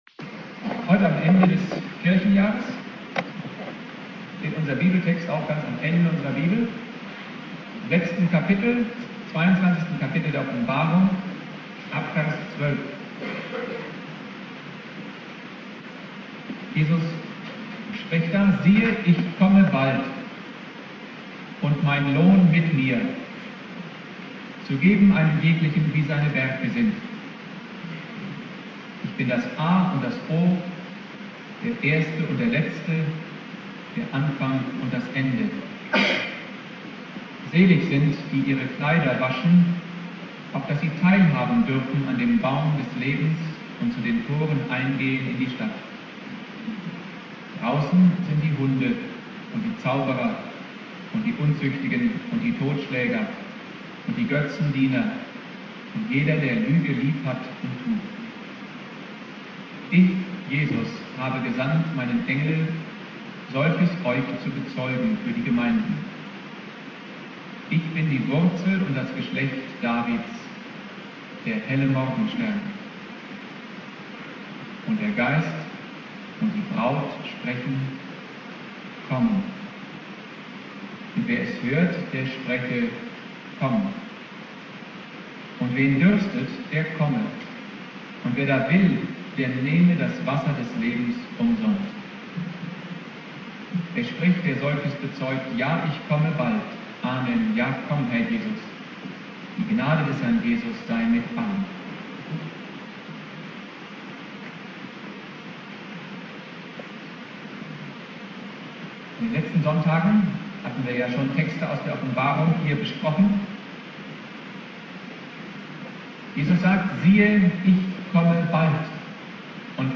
Predigt
Ewigkeitssonntag